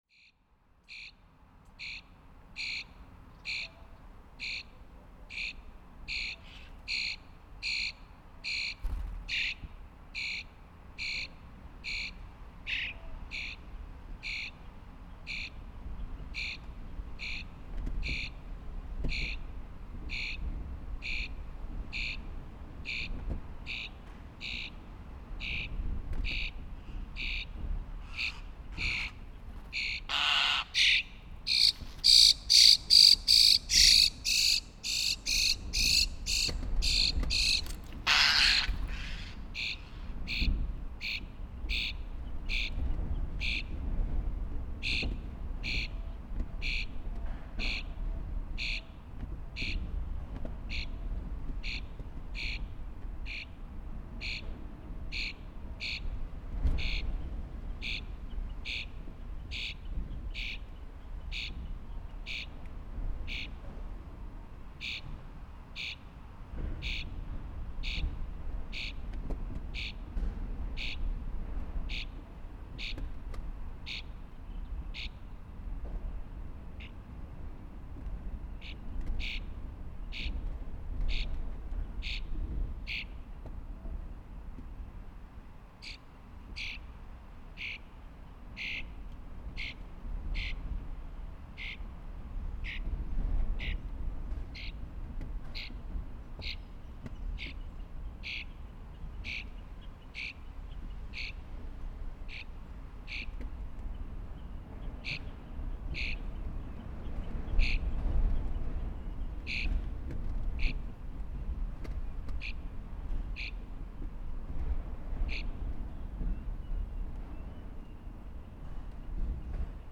The Starling nest inside the barn wall
So I took the opportunity when I noticed a starling nest inside a wall in an abandoned barn to record a birdlife inside. I was able to record through a small hole in the wall but the bird had access to the nest on the outside.